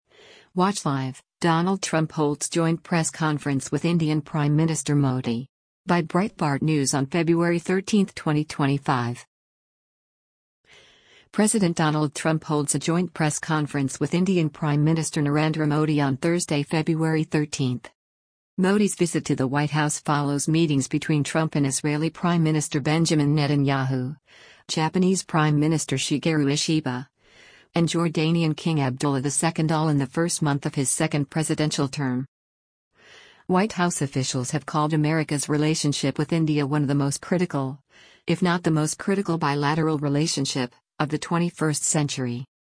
President Donald Trump holds a joint press conference with Indian Prime Minister Narendra Modi on Thursday, February 13.